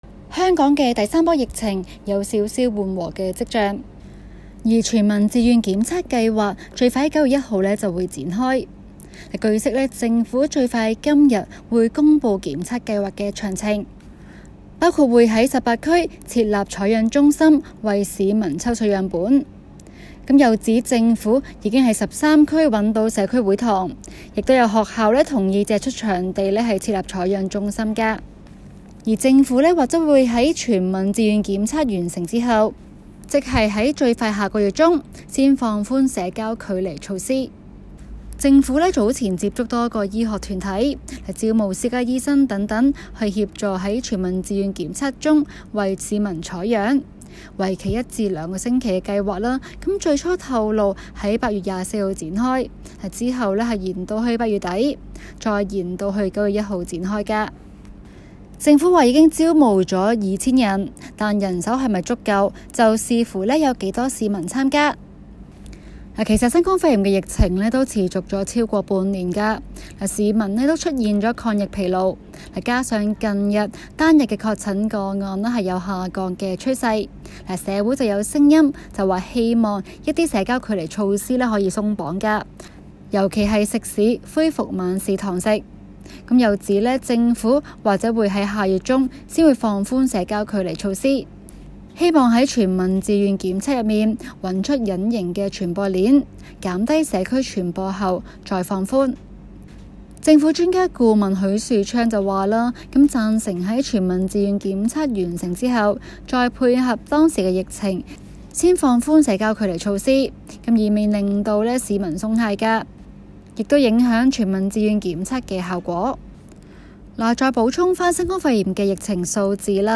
今期 【中港快訊 】環節報道港府推遲至九月才推出全民檢測計劃，到時有望陸續解封。